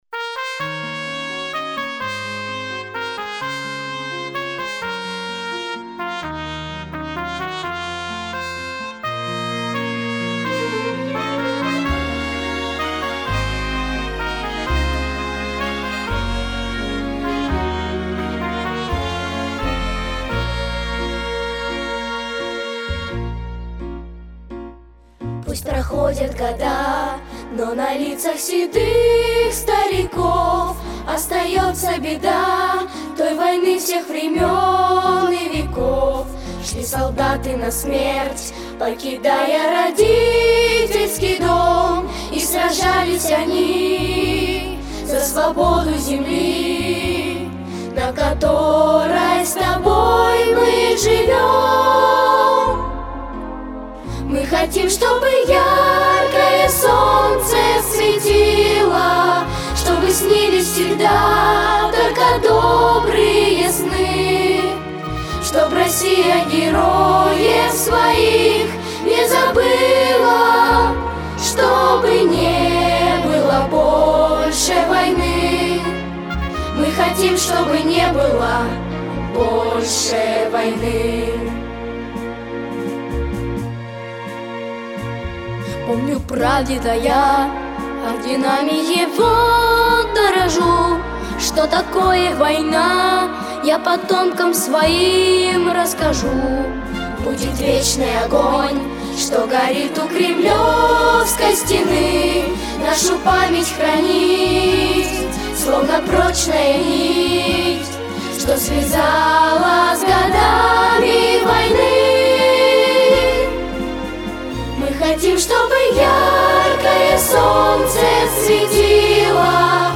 Скачать Слушать минус